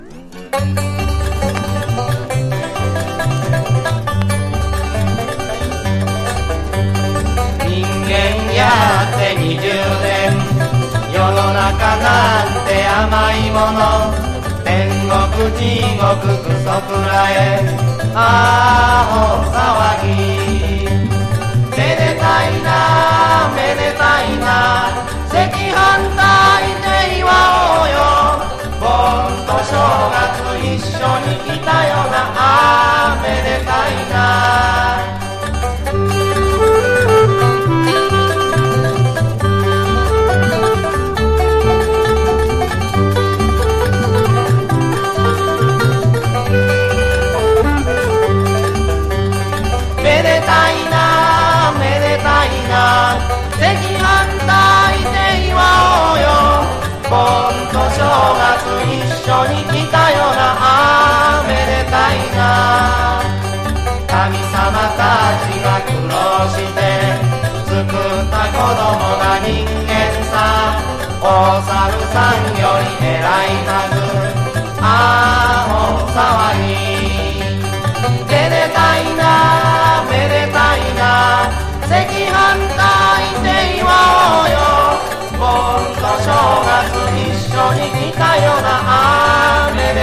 60-80’S ROCK# SSW / FOLK